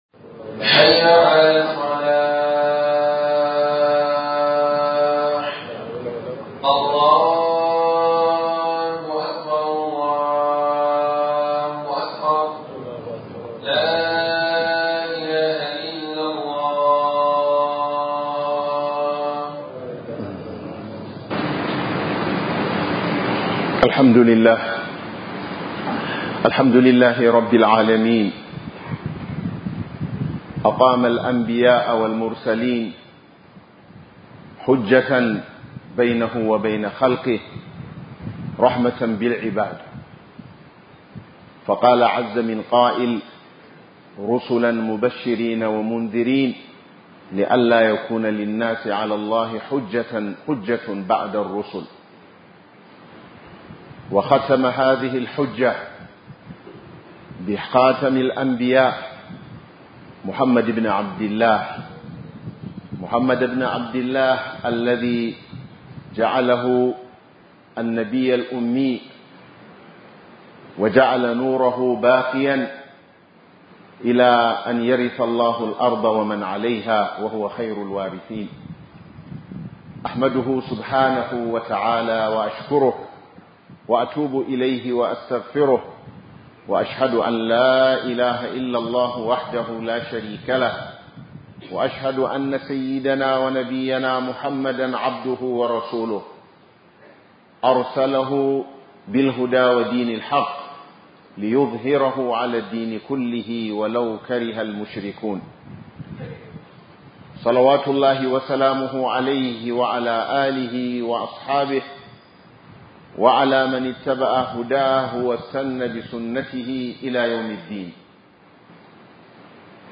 Darasi Daga Majalisin Annabi SAW - HUDUBA